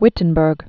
(wĭtn-bûrg, vĭtn-bĕrk)